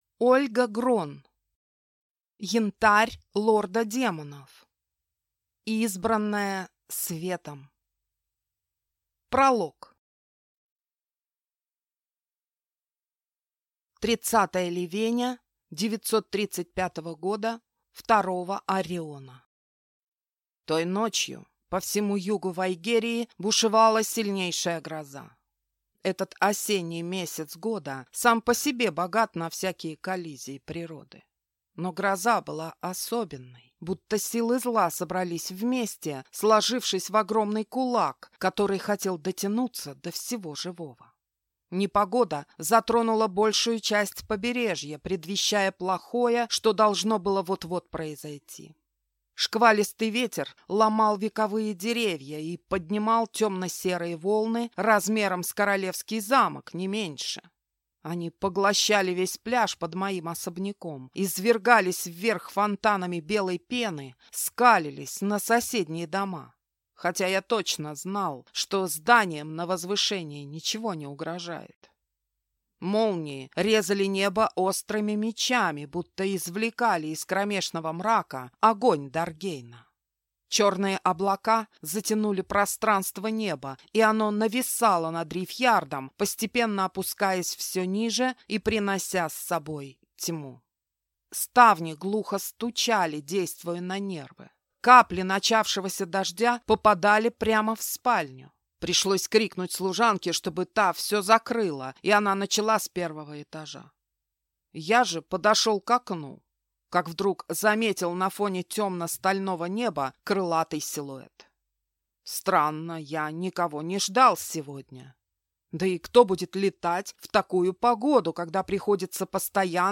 Аудиокнига Янтарь лорда демонов. Избранная светом | Библиотека аудиокниг